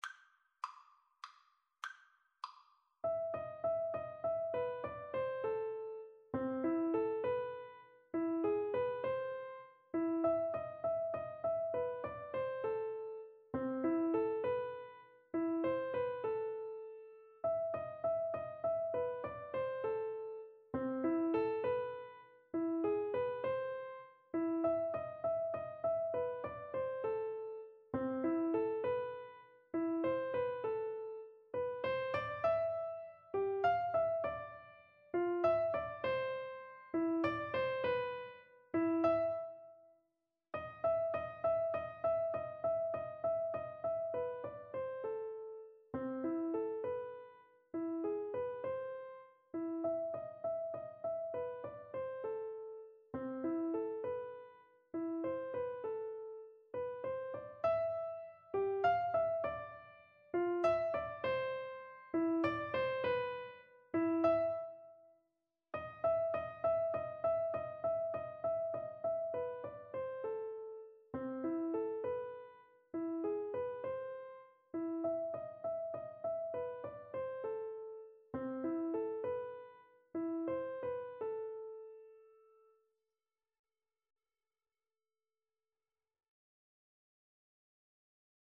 Free Sheet music for Piano Four Hands (Piano Duet)
A minor (Sounding Pitch) (View more A minor Music for Piano Duet )
=150 Moderato
Classical (View more Classical Piano Duet Music)